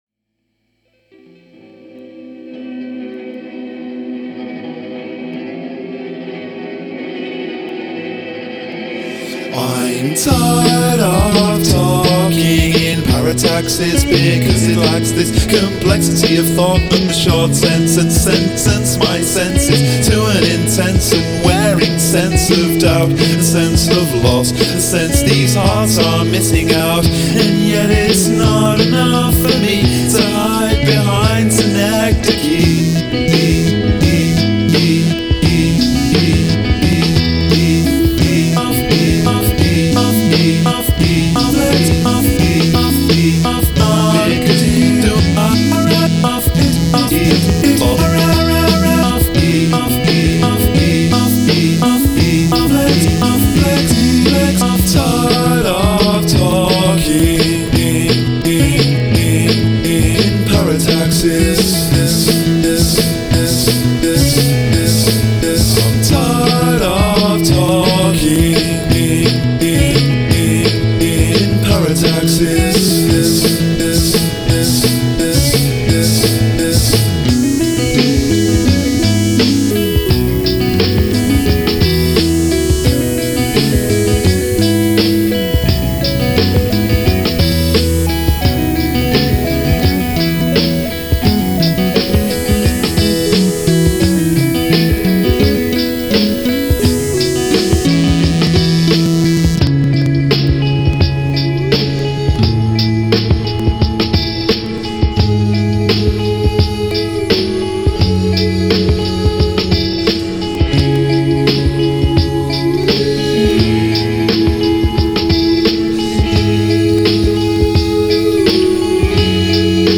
Lyric consists of only one sentence.